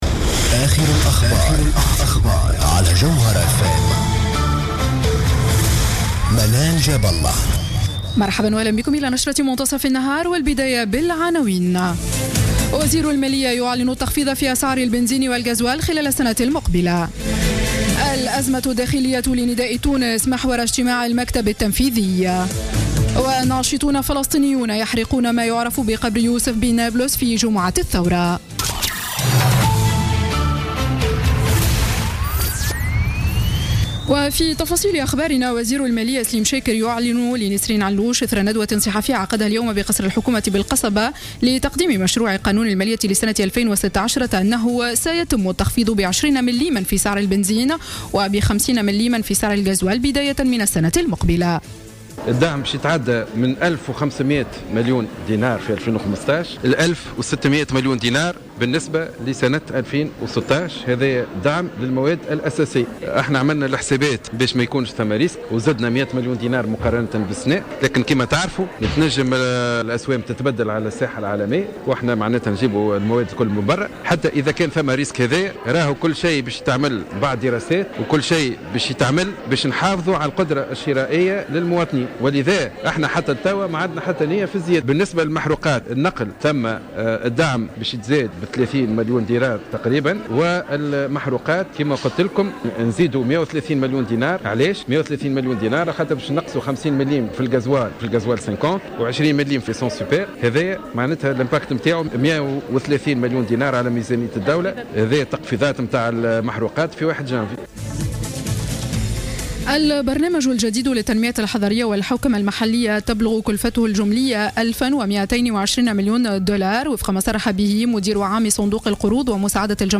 نشرة أخبار منتصف النهار ليوم الجمعة 16 أكتوبر 2015